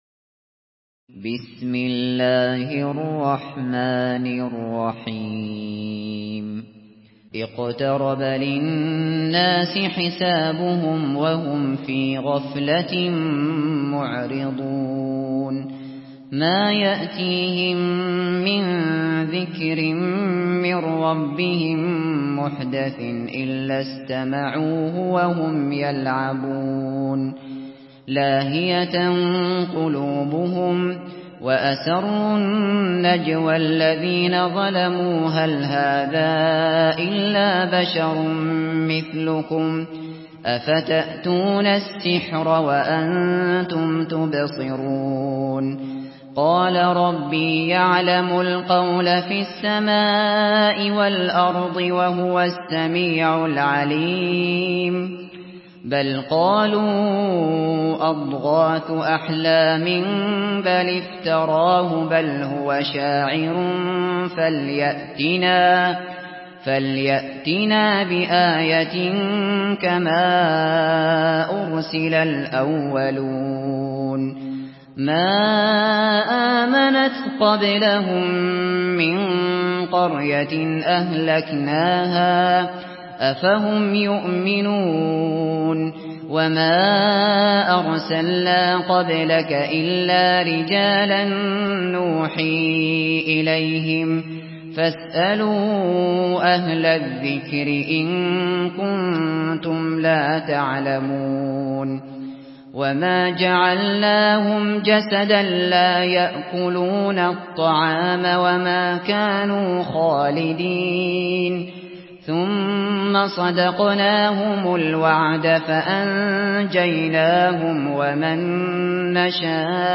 سورة الأنبياء MP3 بصوت أبو بكر الشاطري برواية حفص عن عاصم، استمع وحمّل التلاوة كاملة بصيغة MP3 عبر روابط مباشرة وسريعة على الجوال، مع إمكانية التحميل بجودات متعددة.
مرتل حفص عن عاصم